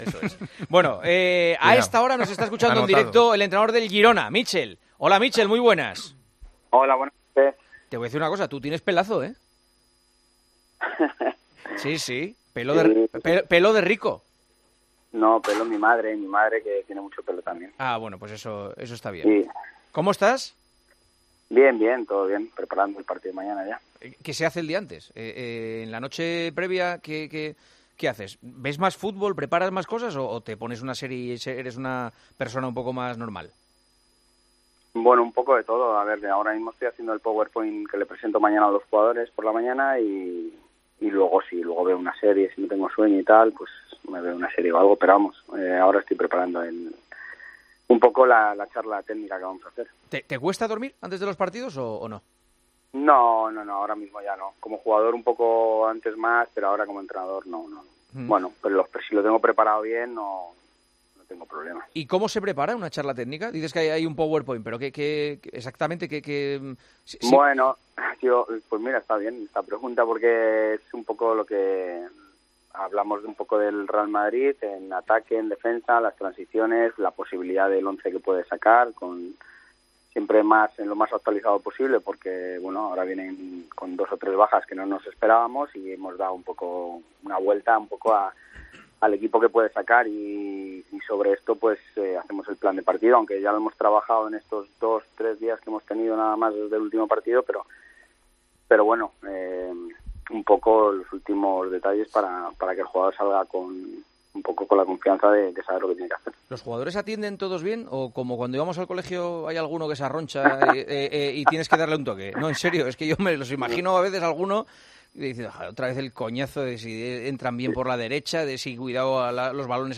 ESCUCHA LA ENTREVISTA A MÍCHEL, EN EL PARTIDAZO DE COPE